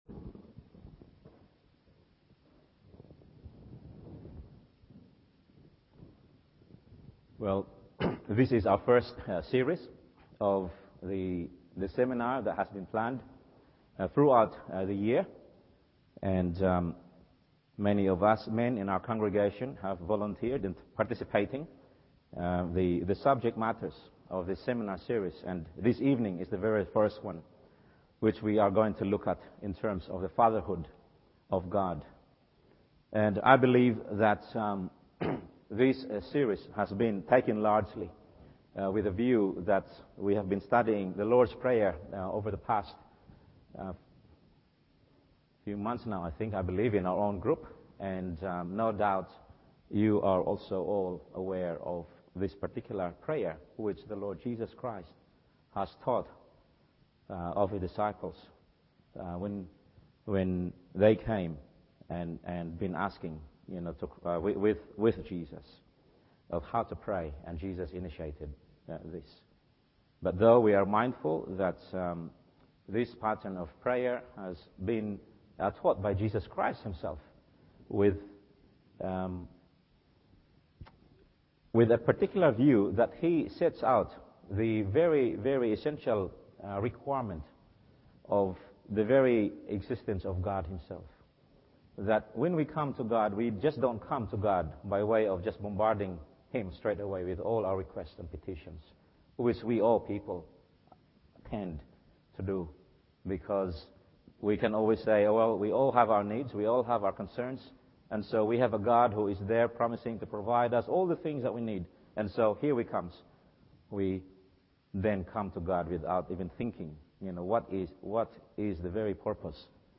Evening Service Mathew 6…